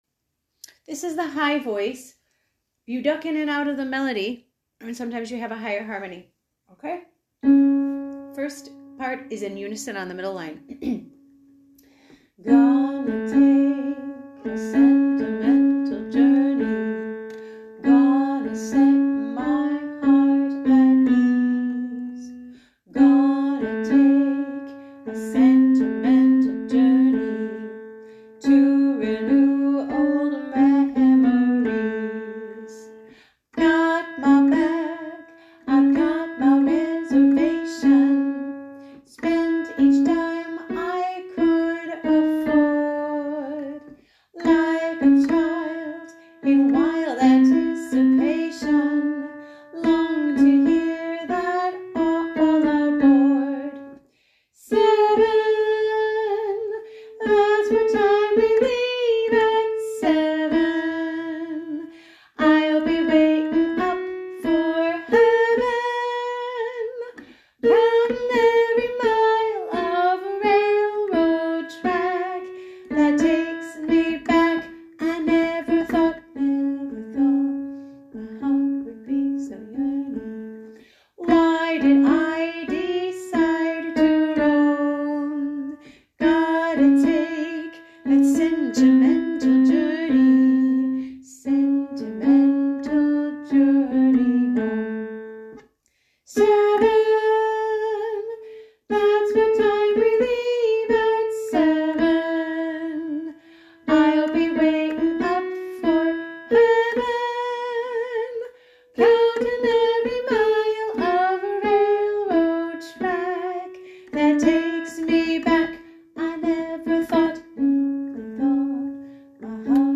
Sing-alongs (mp3):High VoiceMiddle VoiceLow Voice